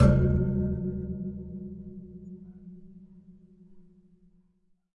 Electric Fan Metal Grill Sampled » efan grill hit 4
描述：An electric fan as a percussion instrument. Hitting and scraping the metal grills of an electric fan makes nice sounds.
标签： metallic reverberation electricfan sample
声道立体声